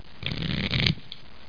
shuffle.mp3